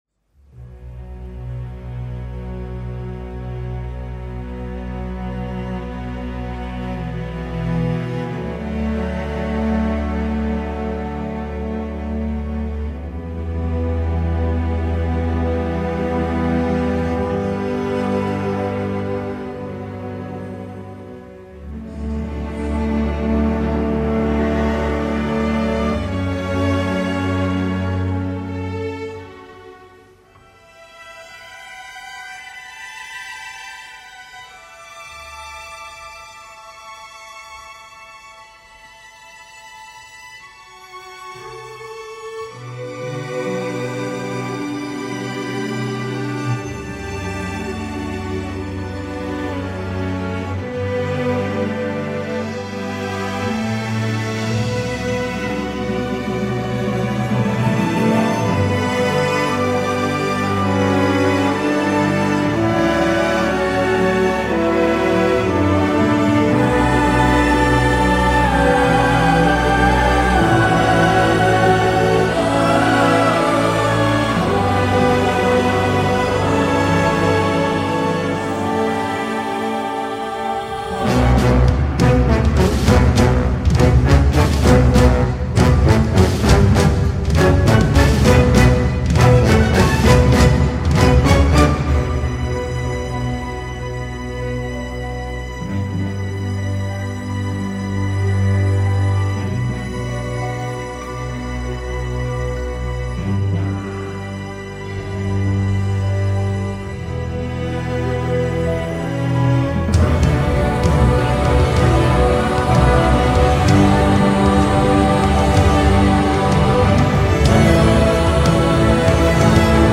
Live-Performance: